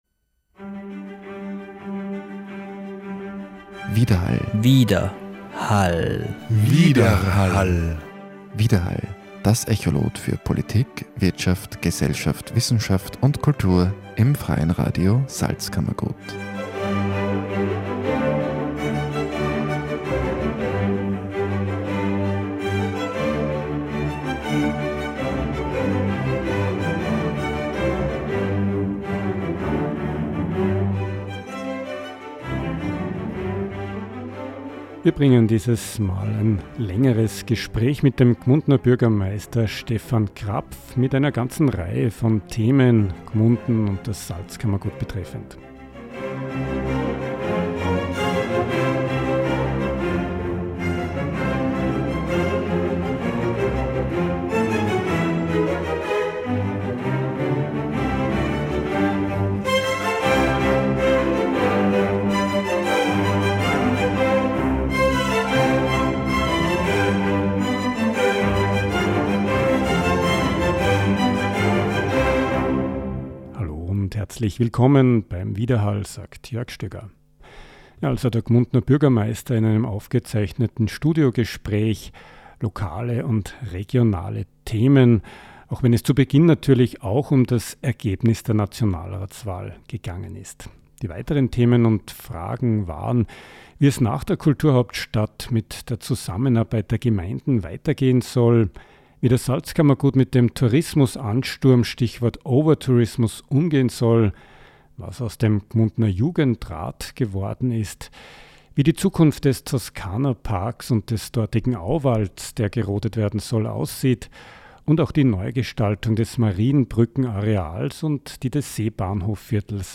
Studiogespräch mit dem Gmundner Bürgermeister Stefan Krapf: Fragen im Studiogespräch waren: – Was bedeutet das Ergebnis der Nationalratswahl für die Lokalpolitik? – Wie hat das Hochwasser Gmunden getroffen?